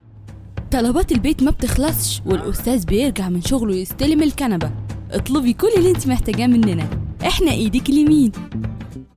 Mısır Arapçası Seslendirme
Kadın Ses